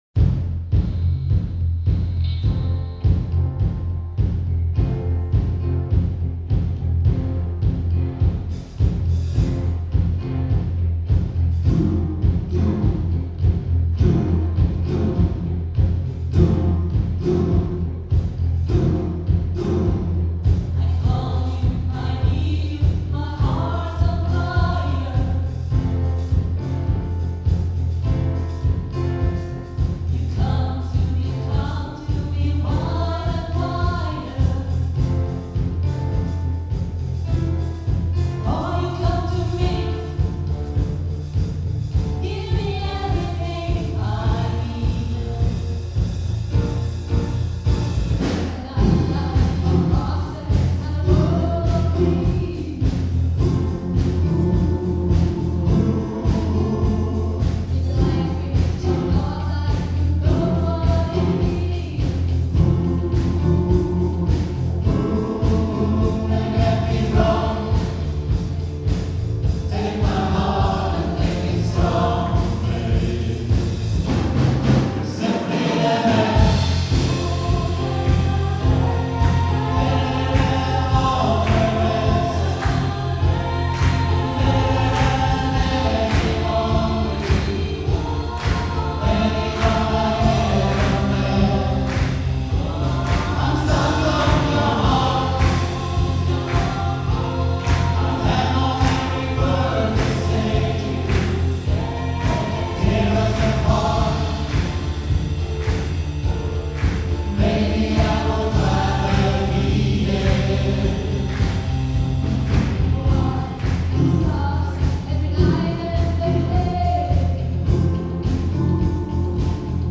Live 2024